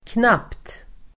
Uttal: [knap:t]